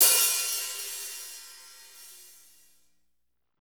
HAT S S O0PR.wav